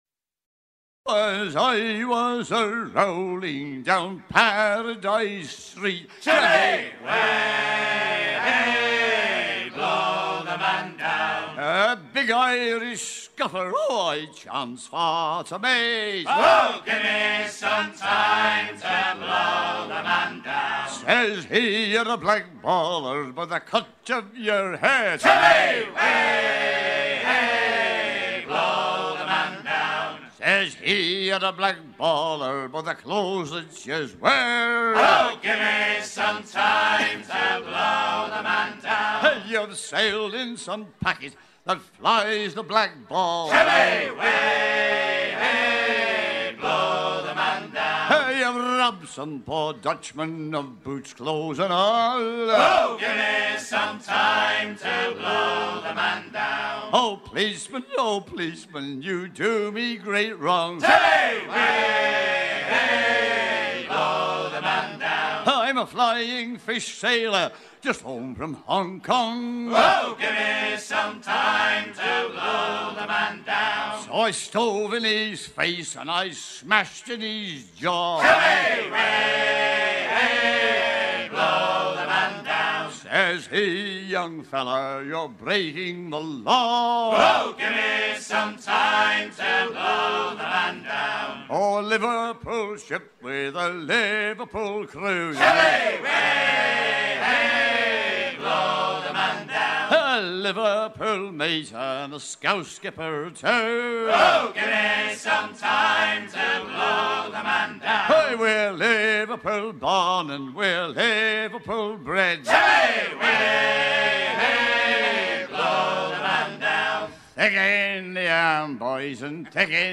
Peut être le plus célèbre de tous les shanties tant à l'époque de la voile qu'au XXe siècle
à hisser main sur main